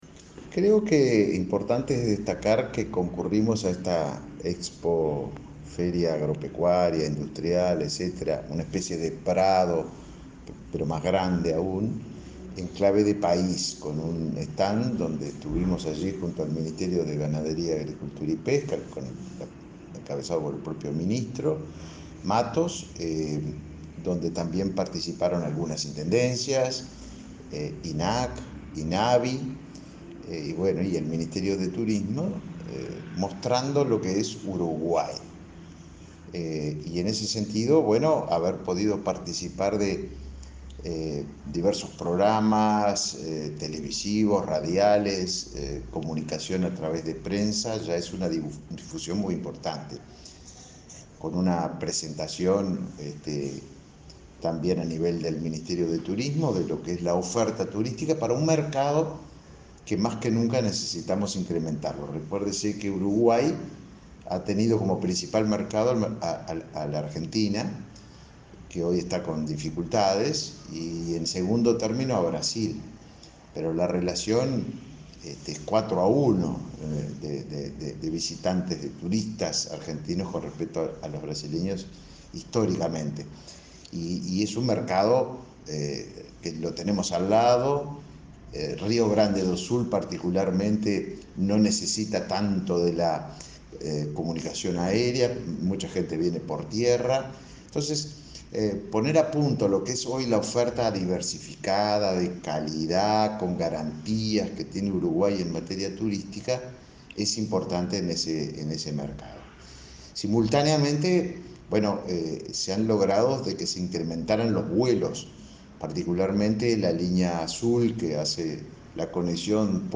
Entrevista al ministro de Turismo, Tabaré Viera